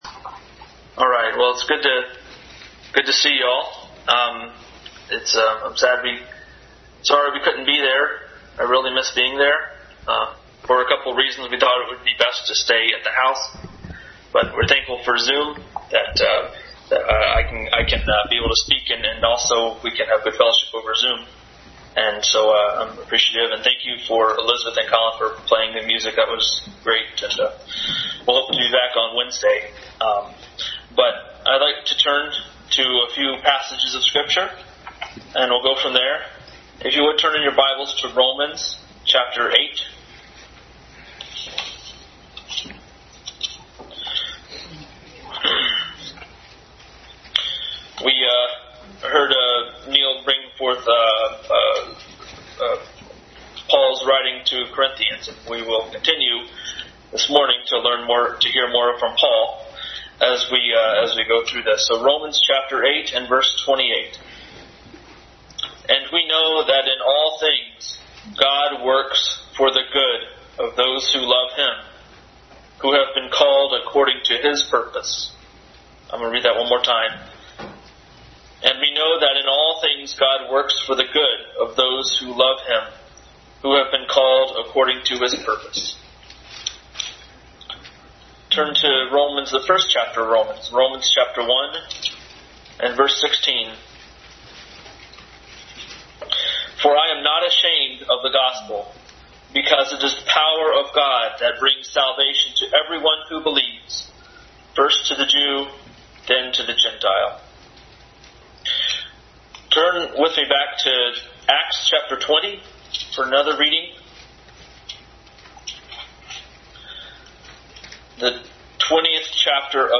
Family Bible Message.
Acts 22 Service Type: Family Bible Hour Family Bible Message.